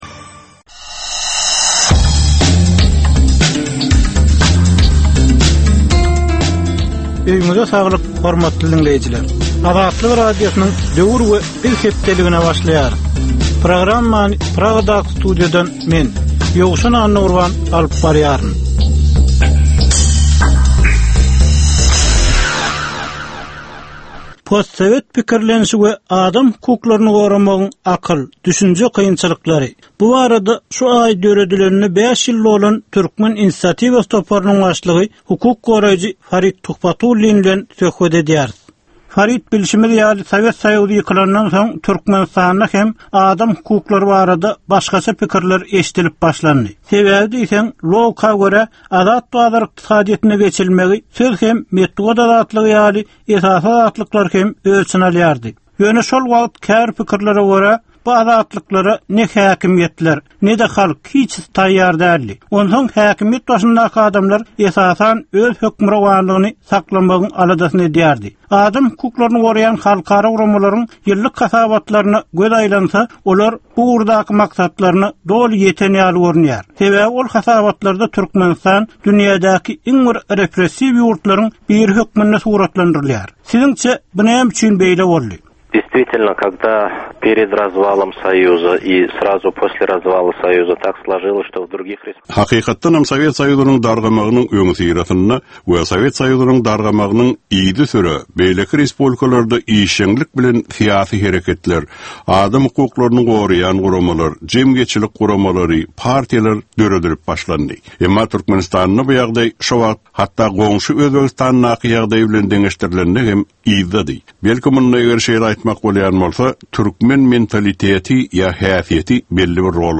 Türkmen jemgyýetindäki döwrüň meseleleri. Döwrüň anyk bir meselesi barada 10 minutlyk ýörite syn-gepleşik. Bu gepleşikde diňleýjiler, synçylar we bilermenler döwrüň anyk bir meselesi barada pikir öwürýärler, öz garaýyşlaryny we tekliplerini orta atýarlar.